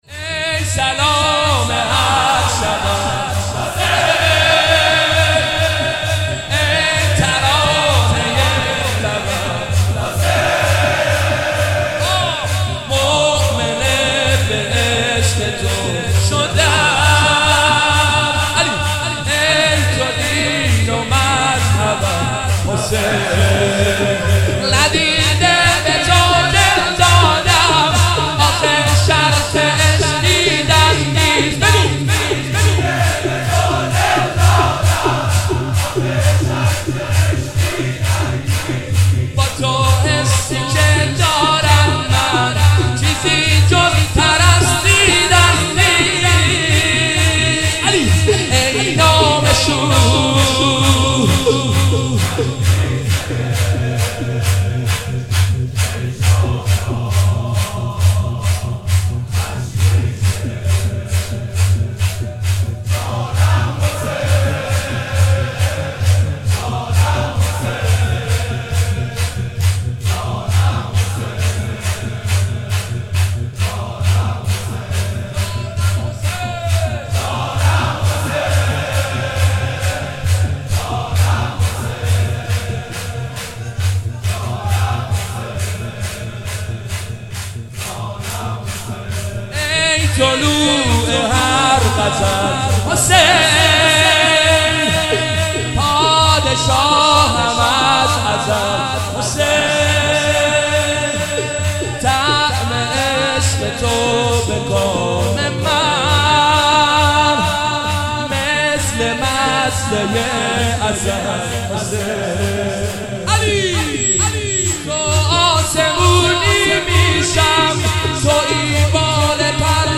شب چهارم محرم97
روضه شب چهارم